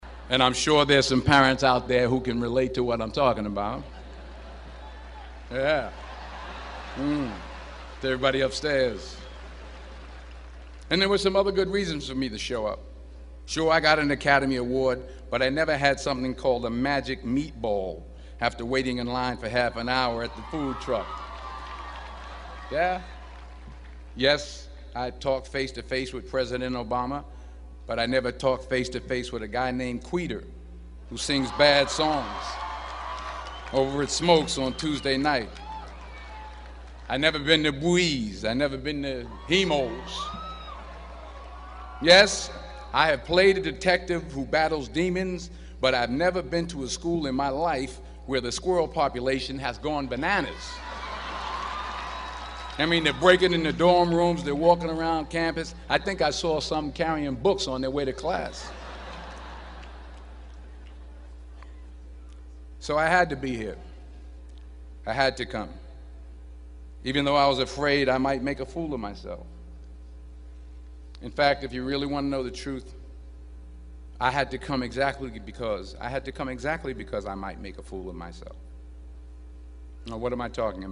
公众人物毕业演讲第421期:丹泽尔2011宾夕法尼亚大学(5) 听力文件下载—在线英语听力室